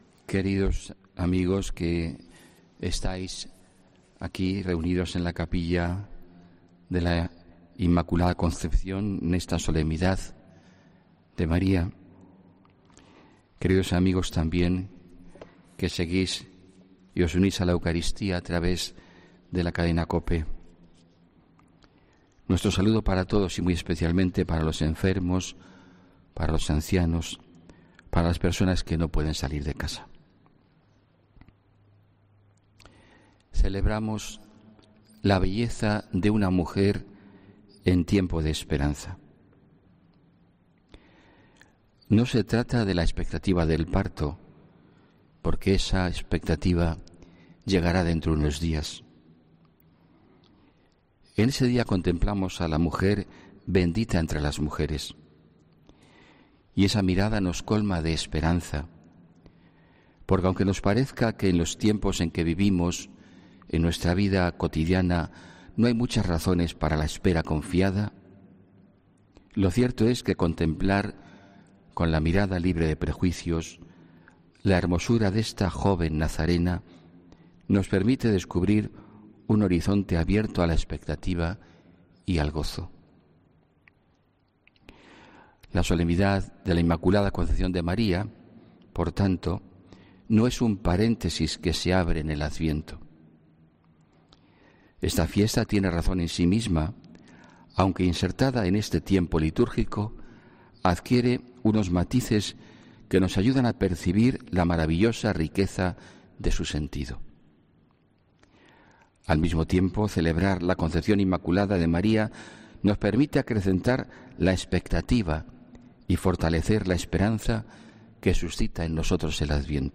HOMILÍA 8 DICIEMBRE 2019